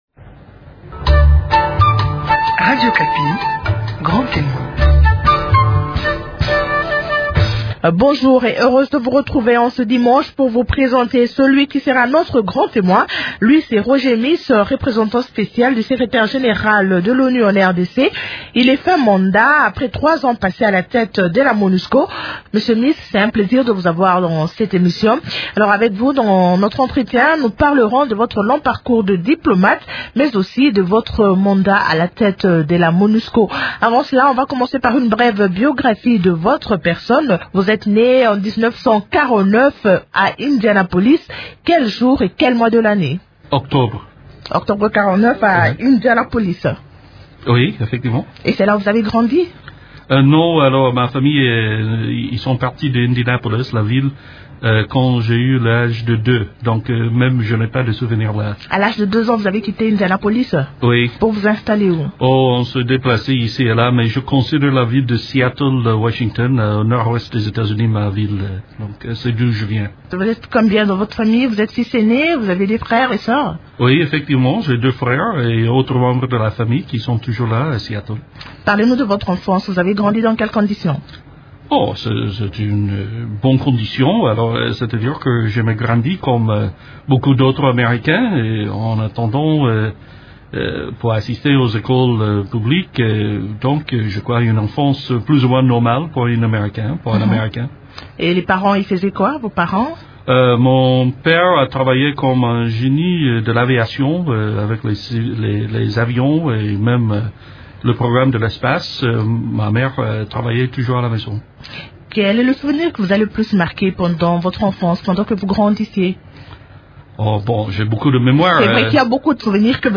Invité de l’émission Grand témoin sur les antennes de Radio Okapi, l’ancien patron de la Mission des Nations unies pour la stabilisation en RDC dit quitter la mission avec un sentiment partagé entre l’échec et l’optimisme.